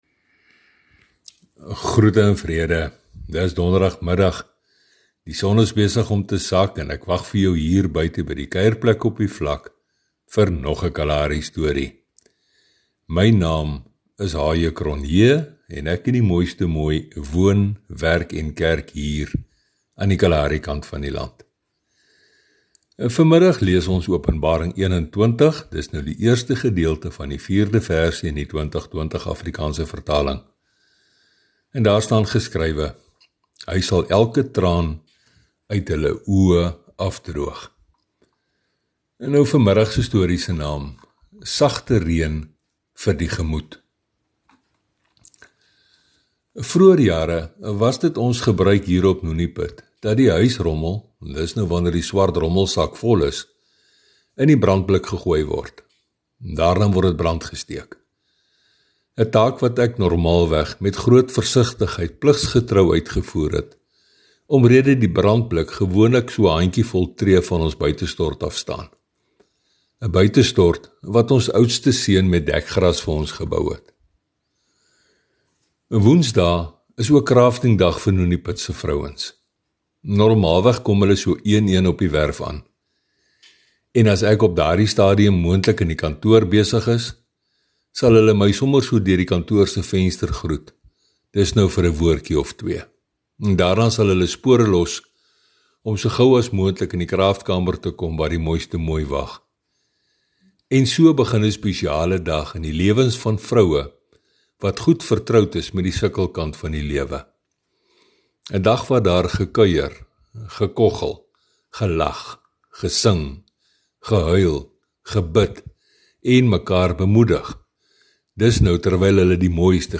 Sy getuienis verhale het 'n geestelike boodskap, maar word vertel in daardie unieke styl wat mens slegs daar op die kaal vlaktes kan optel. Vandag se storie se naam is “Liefhê is ‘n doending” uit 1 Pet 4:8,10.